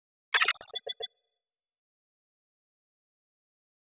Futurisitc UI Sound 17.wav